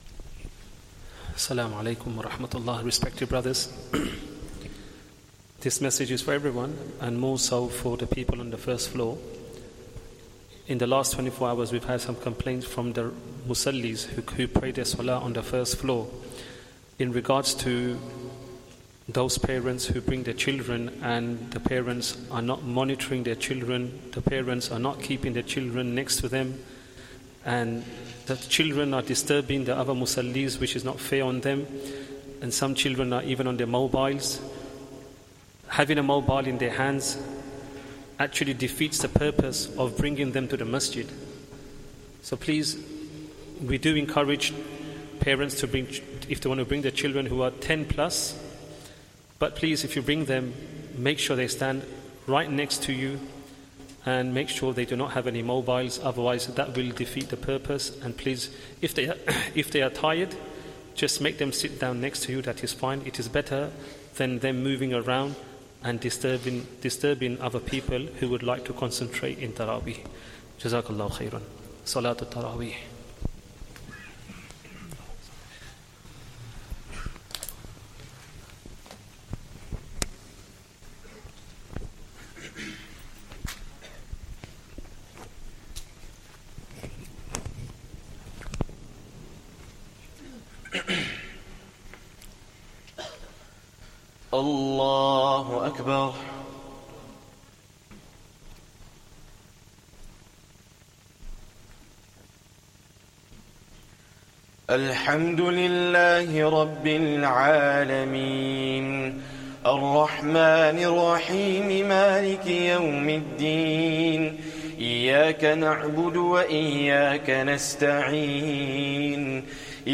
Taraweeh Prayer 11th Ramadhan